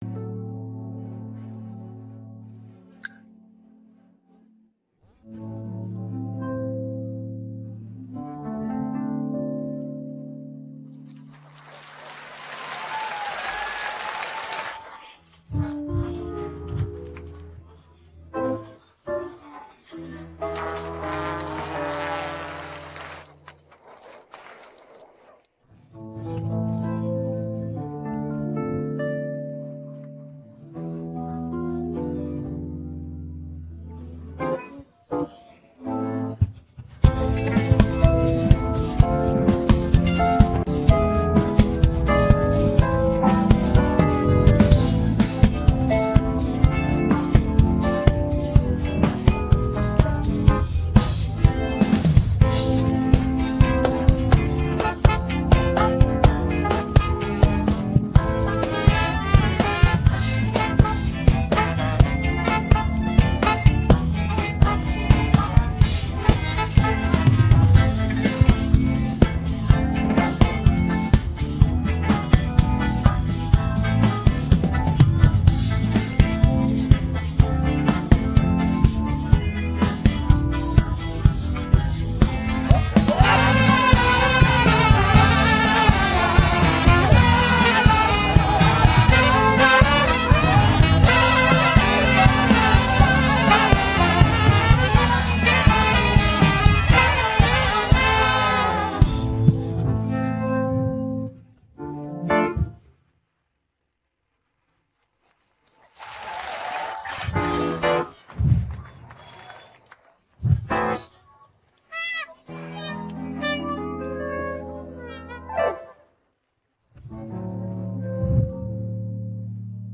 dance-pop караоке 16